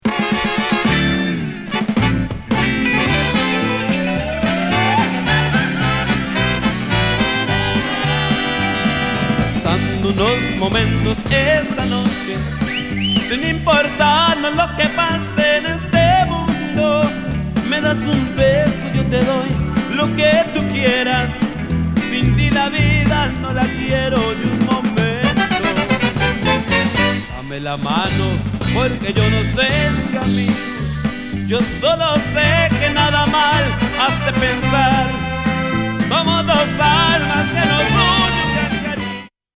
ホーンもナイスな陽気なマリアッチなスパニッシュで歌うランチェーラ曲